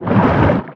Sfx_creature_pinnacarid_push_01.ogg